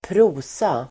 Ladda ner uttalet
Uttal: [²pr'o:sa]